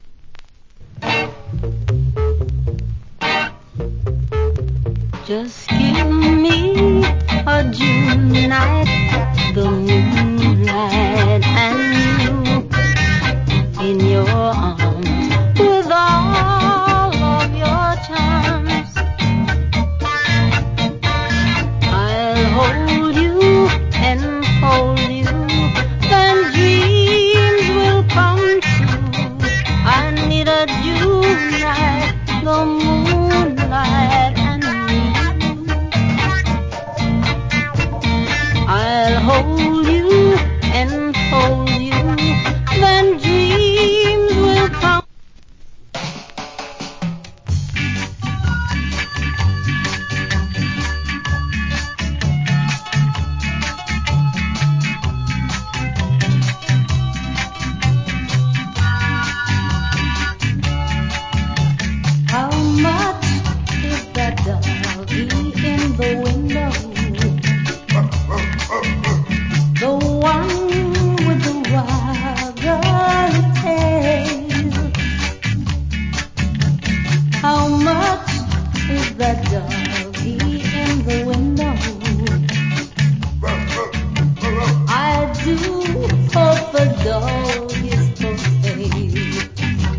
Wicked Early Reggae.